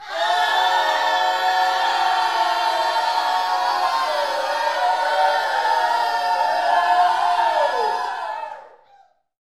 UYCSCREAMS.wav